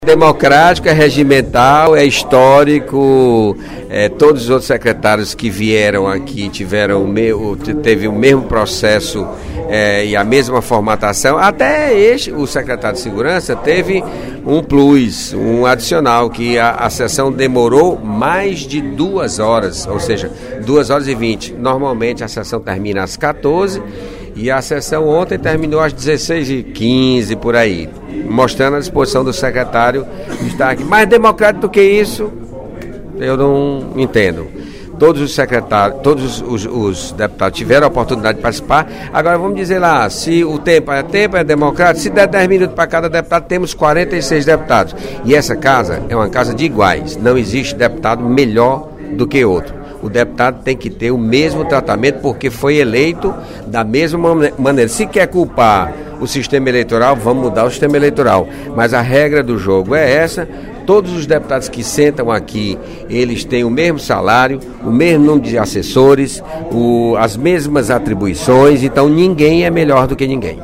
No primeiro expediente da sessão plenária desta quinta-feira (08/08), o deputado José Sarto (PSB), líder do Governo na Assembleia Legislativa, rebateu as críticas do deputado Heitor Férrer (PDT) sobre a visita do secretário de Segurança Pública, Francisco Bezerra, na quarta-feira (07/08).